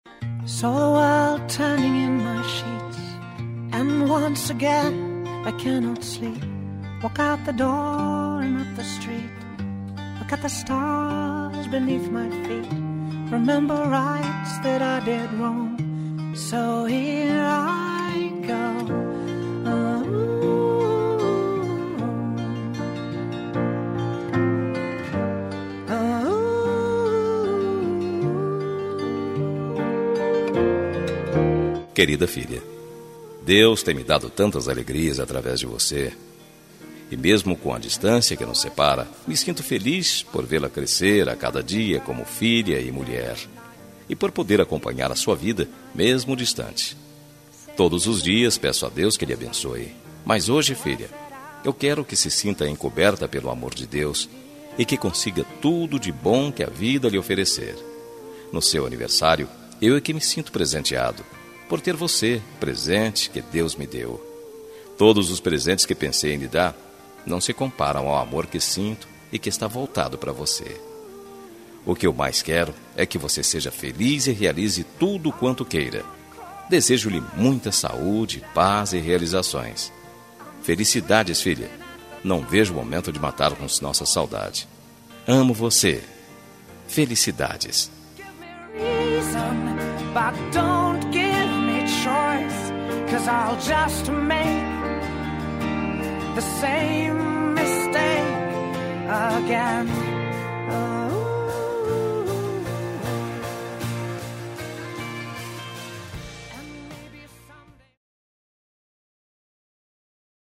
Telemensagem de Aniversário de Filha – Voz Masculina – Cód: 1796 – Distante